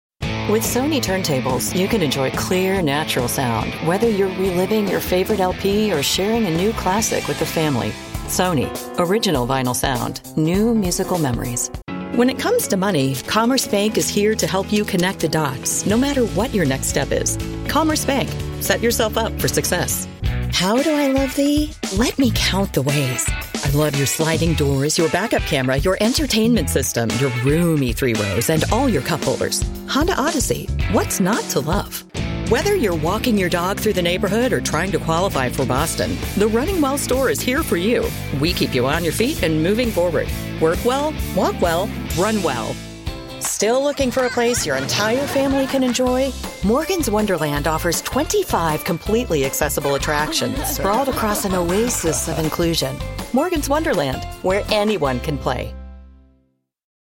Commercial
VO Demos